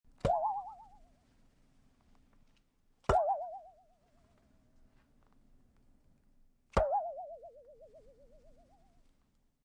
Cartoon_boing Sound Button: Unblocked Meme Soundboard